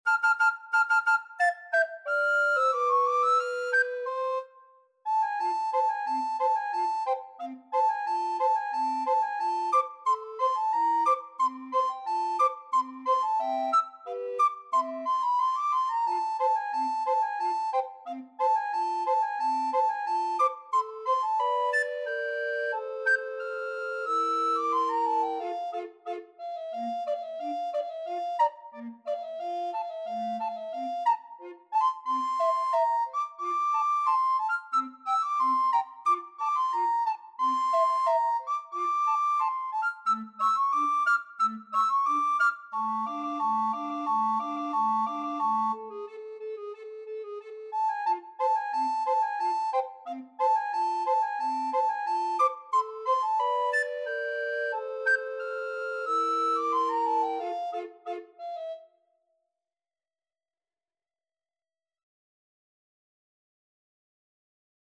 Soprano RecorderAlto RecorderBass Recorder
Allegro =180 (View more music marked Allegro)